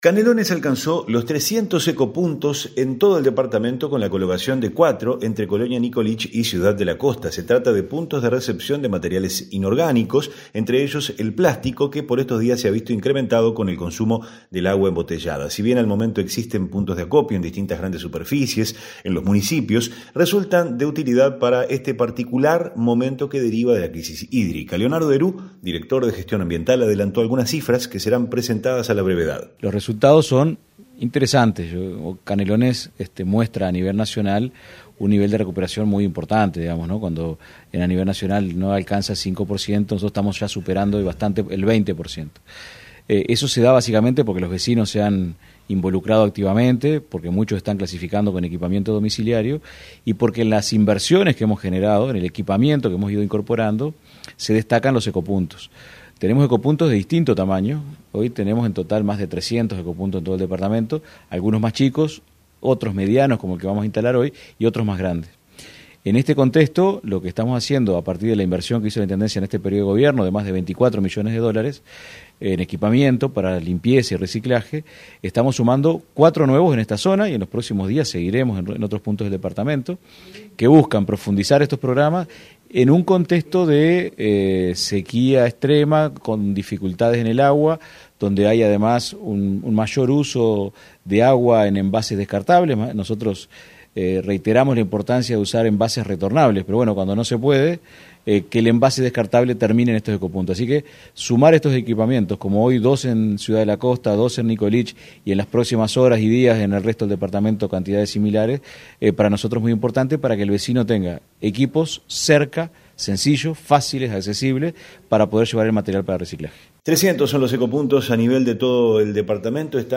Leonardo Herou, director de Gestión Ambiental de Canelones, adelantó algunas cifras de las que serán presentadas en breve.
REPORTE-ECOPUNTOS.mp3